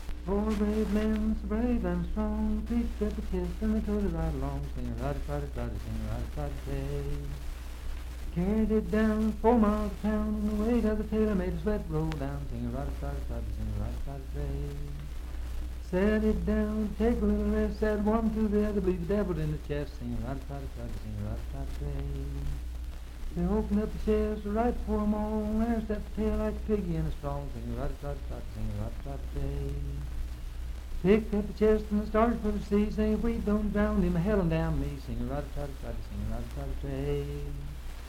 Unaccompanied vocal music
Verse-refrain 5d(3w/R).
Voice (sung)
Marlinton (W. Va.), Pocahontas County (W. Va.)